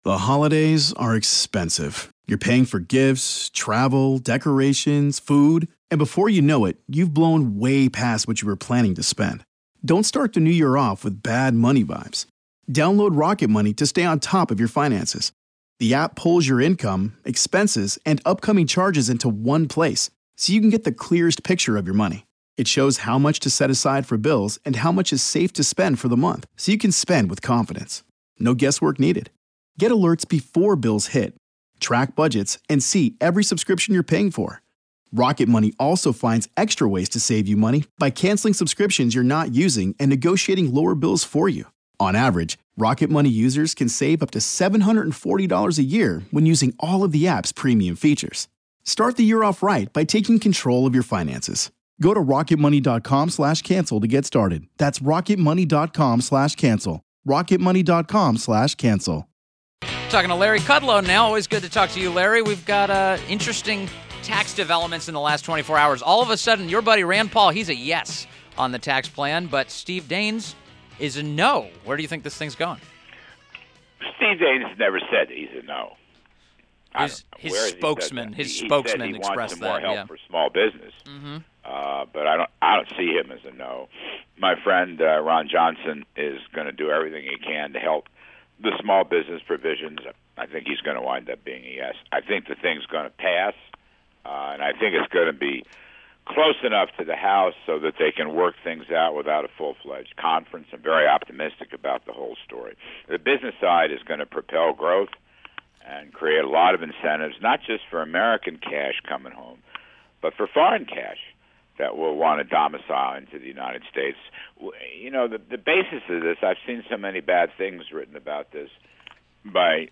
WMAL Interview - LARRY KUDLOW - 11.28.17